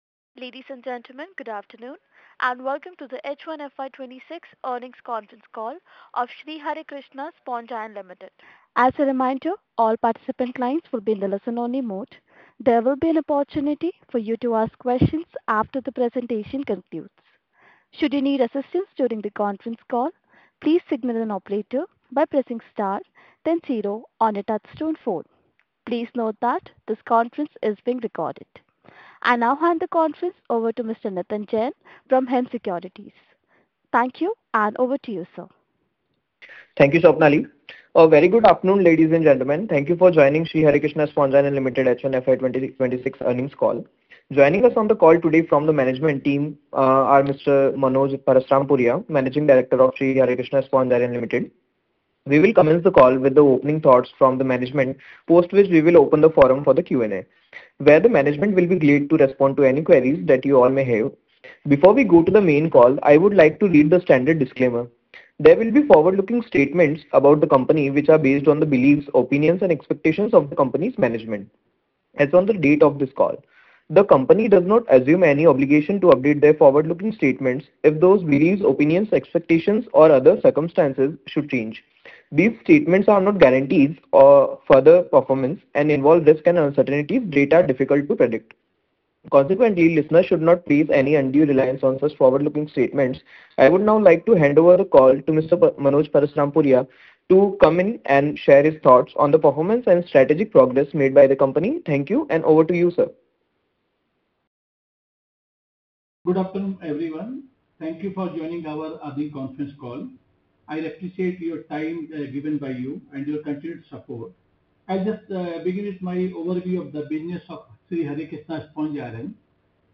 Audio Recording of Earning call